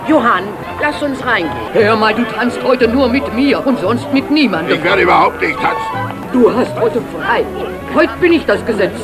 Ich hätte da mal eine Münchner Trick-Synchro von 1989 mit den üblichen Verdächtigen.
Frau des Kommissars (mit Norbert Gastell)